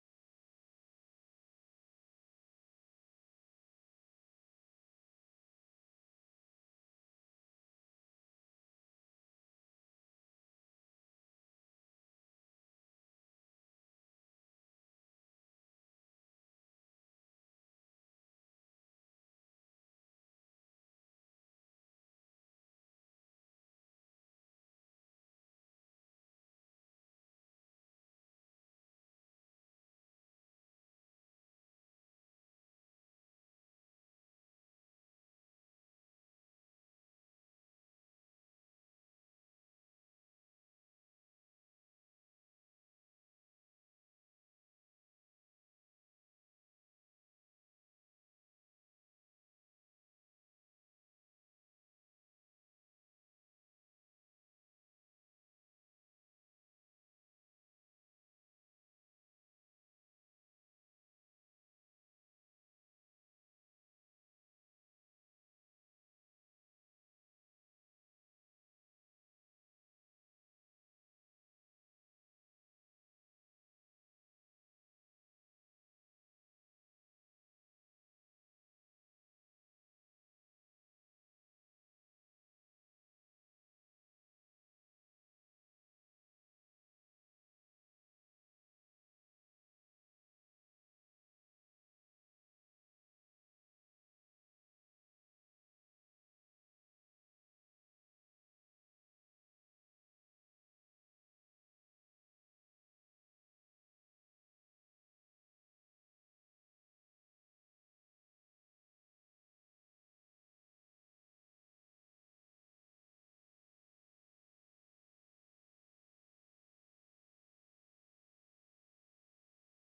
Město Litvínov: 15. zasedání Zastupitelstva města 27.05.2024 fff876702dfa5801ea82fede1b94113c audio